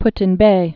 (ptĭn-bā)